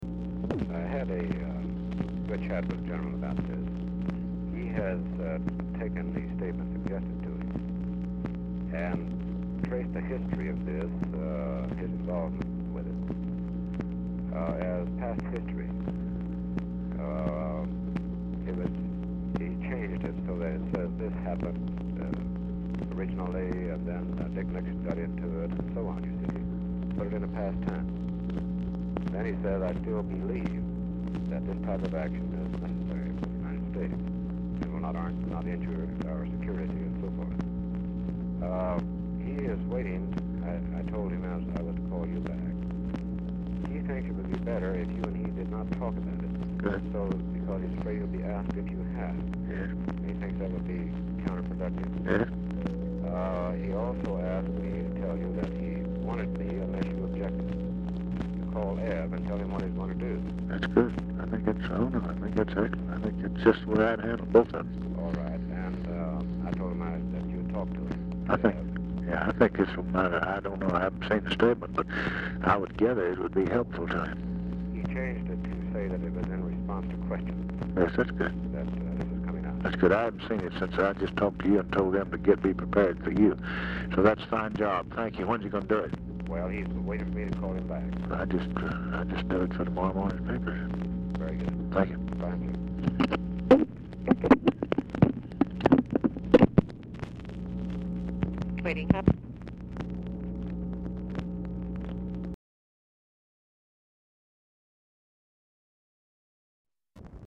Telephone conversation # 11507, sound recording, LBJ and BRYCE HARLOW, 2/2/1967, 1:27PM | Discover LBJ
RECORDING STARTS AFTER CONVERSATION HAS BEGUN
Format Dictation belt
Location Of Speaker 1 Cabinet Room, White House, Washington, DC